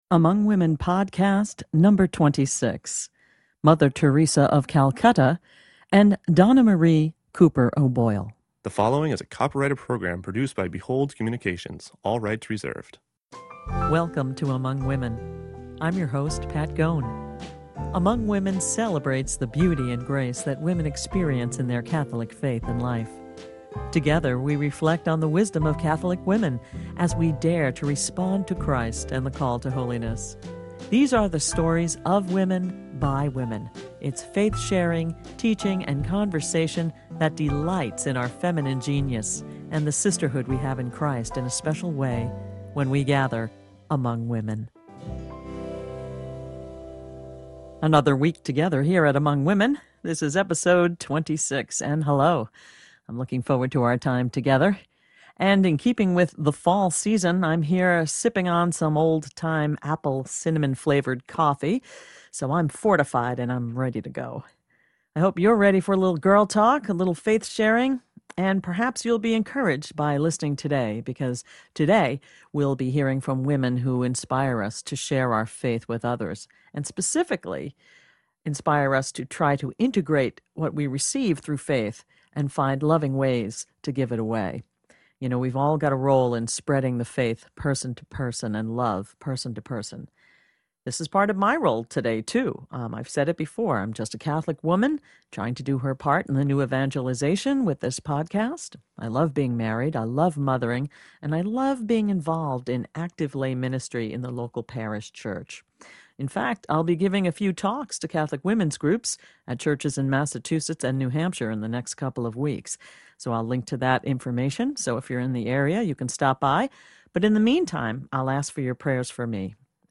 You'll also hear Mother Teresa's beutiful inspiring voice!